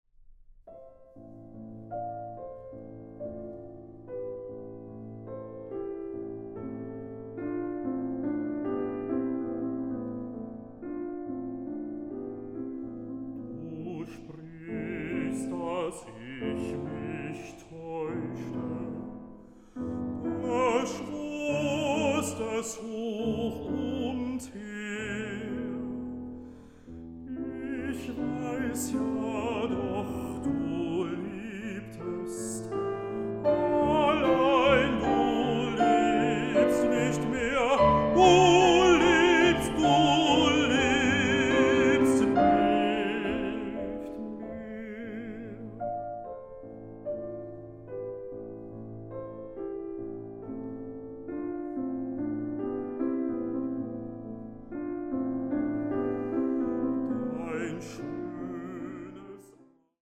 Recording: Mendelssohn-Saal, Gewandhaus Leipzig, 2025